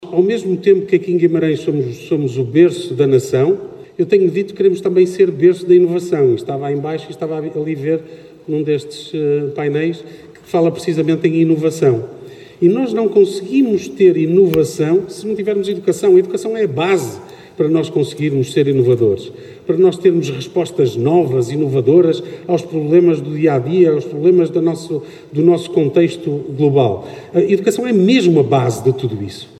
O Education Summit arrancou ontem no pavilhão multiusos. O presidente da Câmara de Guimarães, Ricardo araújo, deu as boas vindas aos 2400 participantes destacando a importância da educação no desenvolvimento dos territórios.